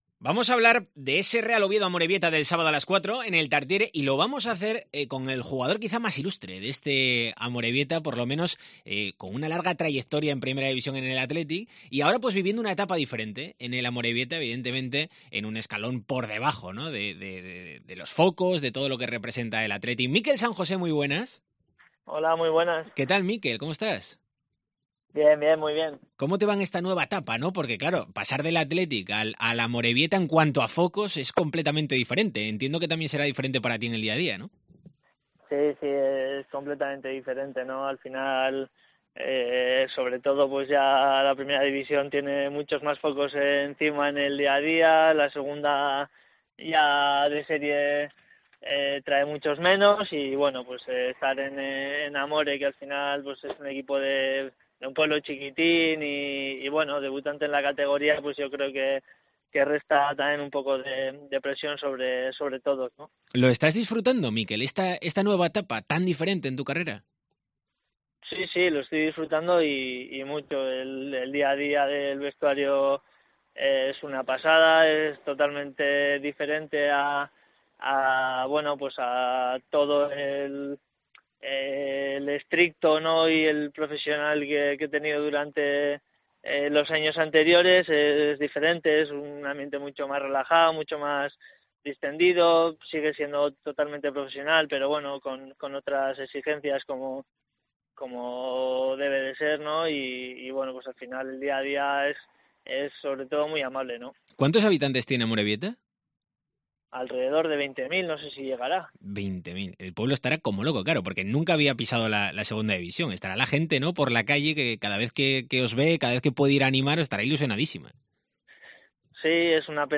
Entrevista a Mikel San José en DCA